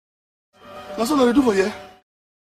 Na so una dey do for here (sound effect for comedy) 🤣
Comedy sound effect 🤣 Na so una dey do for here – Sound effect for comedy.
Na-so-una-dey-do-for-here-Comedy-sound-effect.mp3